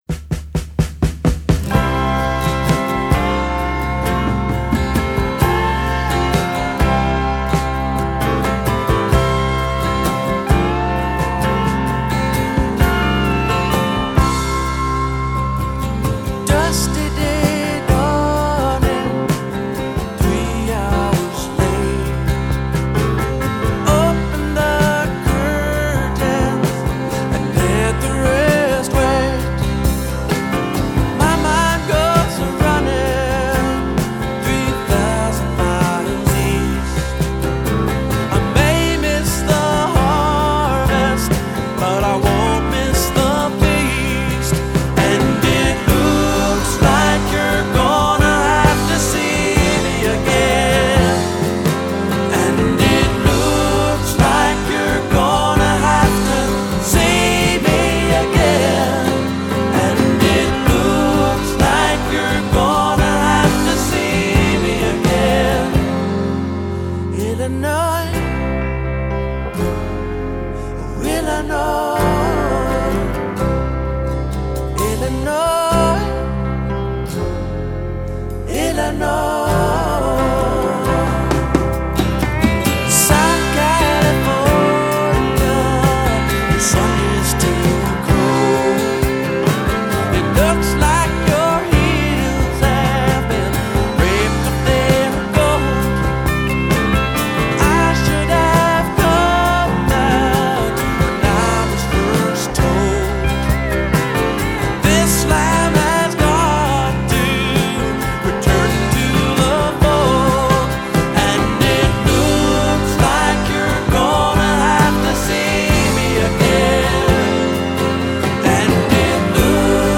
★ 美國最具代表性創作者之一，以輕柔歌聲、動人歌詞與吉他走紅70年代的民謠詩人！